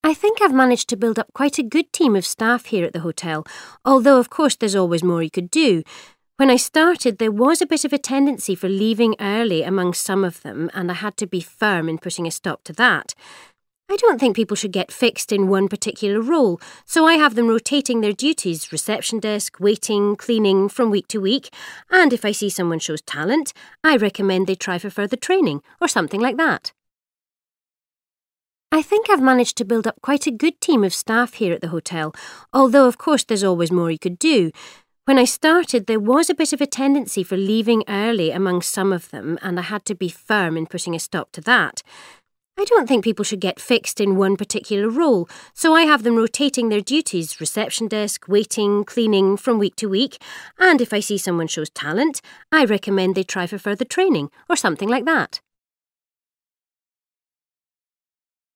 1. You hear a hotel manager talking about the staff who work for her. What does she say about them?